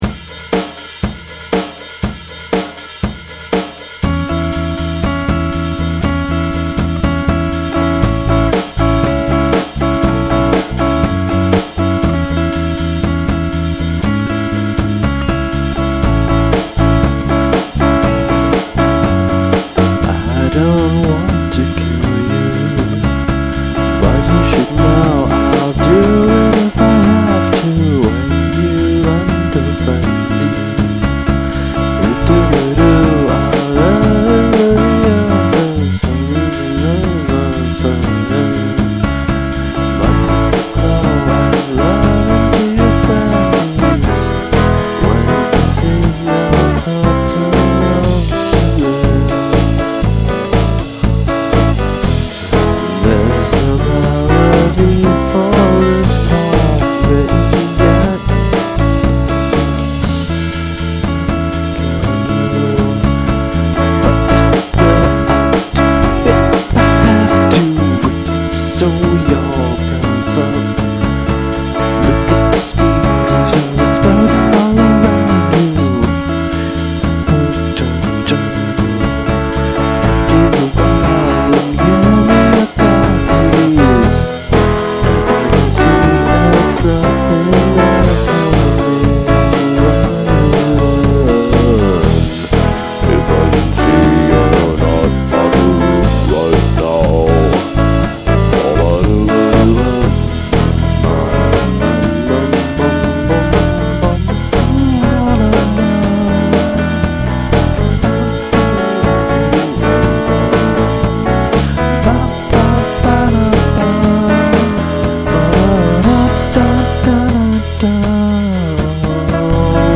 These are all just "test" lyrics while trying to figure out a melody.
(mumbling - additional transcription is left as an exercise for the reviewer)
Another old unfinished Para-chestnut.
My favorite part is that second guitar, which was pretty fancy for me at the time. That, and the oh-so-indie contrapuntal bass.